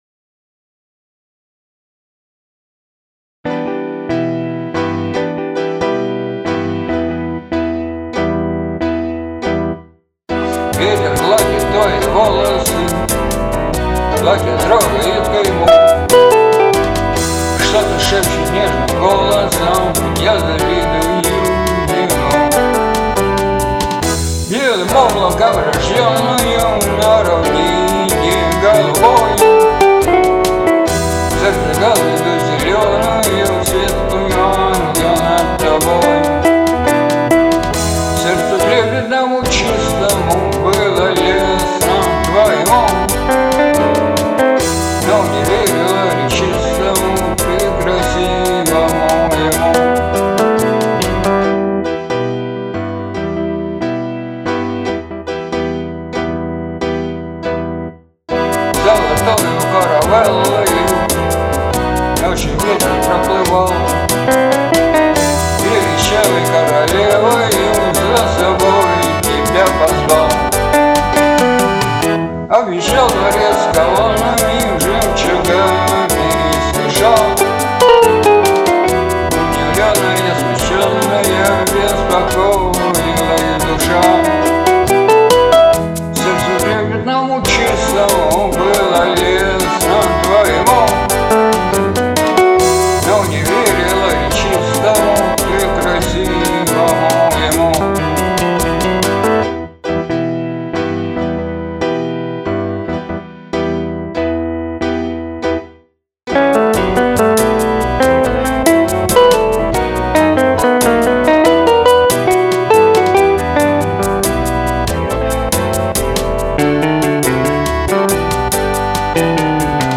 • Жанр: Легкая